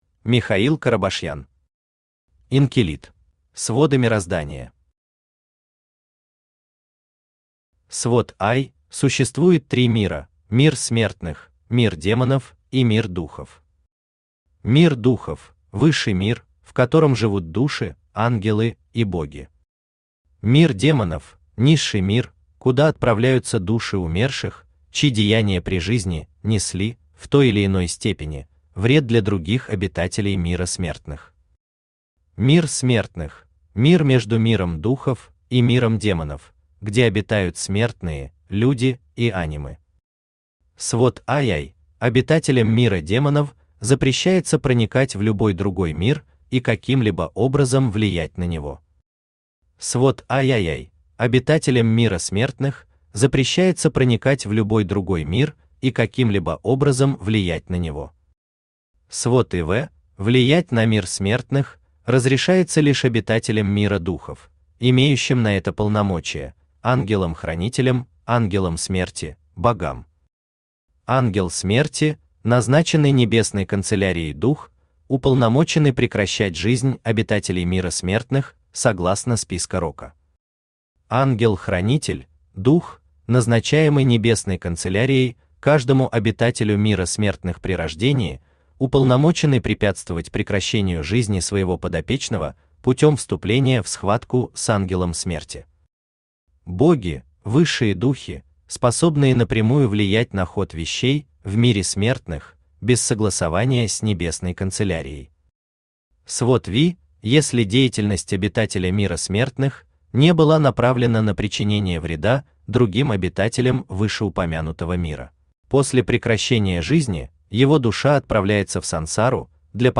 Aудиокнига Инкилит Автор Михаил Семёнович Карабашьян Читает аудиокнигу Авточтец ЛитРес.